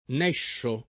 nescio [ n $ ššo ]